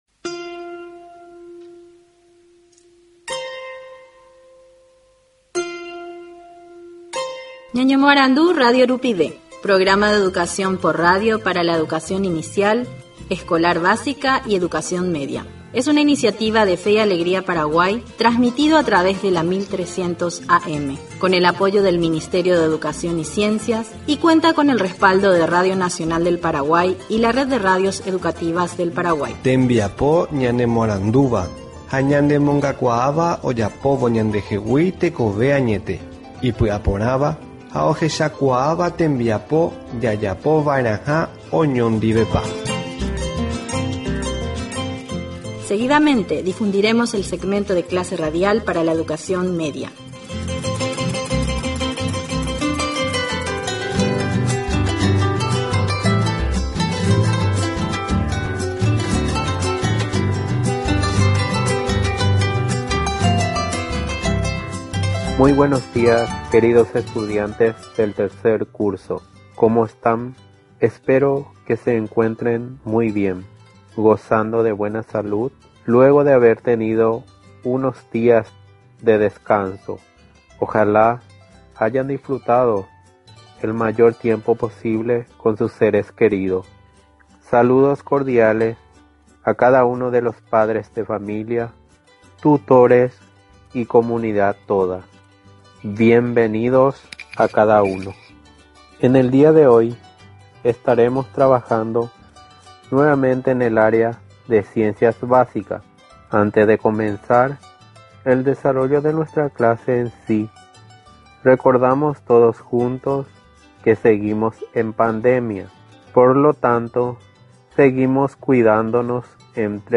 Ñañomoarandu Radio Rupive Día Martes. 3er año – Química.